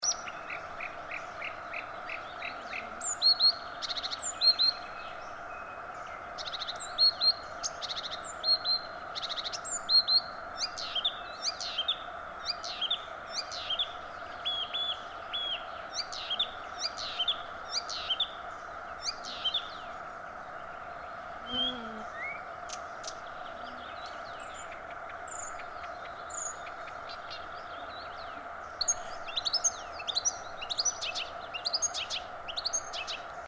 Imitations in recorded song of hybrid Marsh x Blyth's Reed Warbler Acrocephalus palustris x dumetorum, Espoo, Finland, 19 June 2003
0:00 European Bee-eater Merops apiaster 'gru-gru-gru', with one unidentified high note
0:10 Song of Fork-tailed Drongo Dicrurus adsimilis
0:14 loud 'pie-pie', unidentified
0:28 European Goldfinch Carduelis carduelis, mixed with 'sparrow' type calls